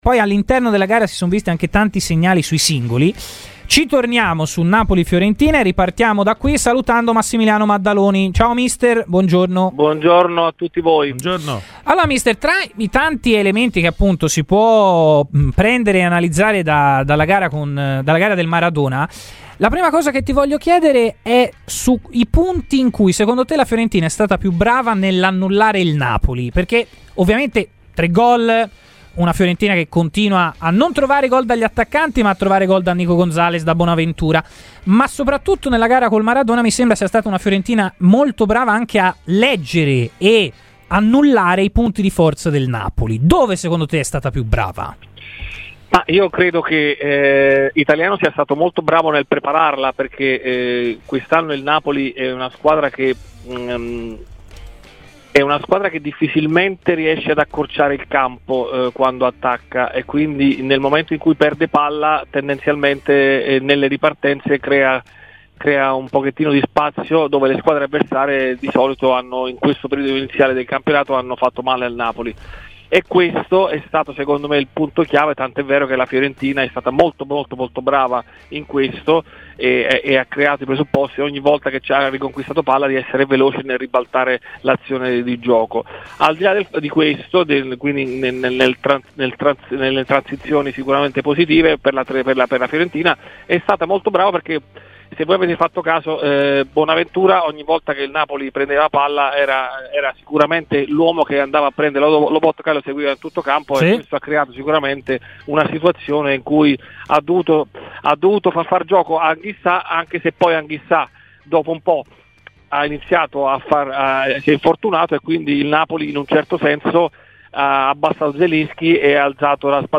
trasmissione in onda su Radio FirenzeViola